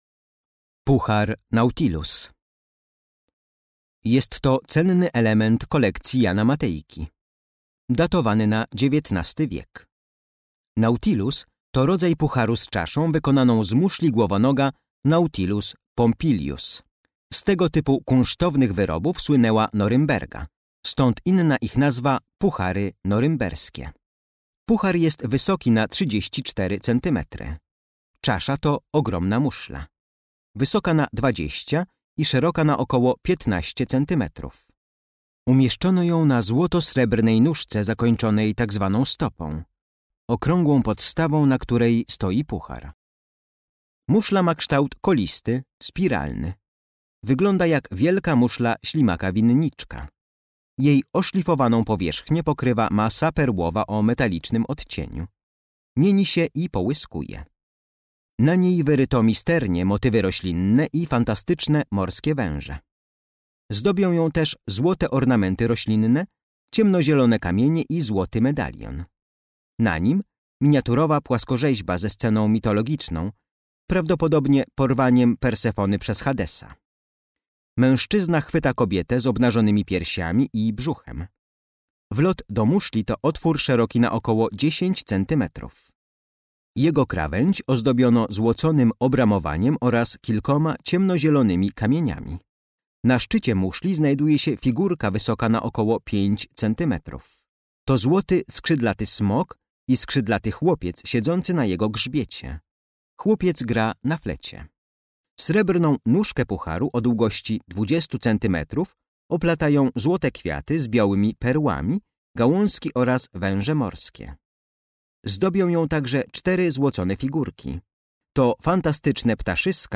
Audiodeskrypcja dla wybranych eksponatów z kolekcji MNK znajdujących się w Domu Jana Matejki.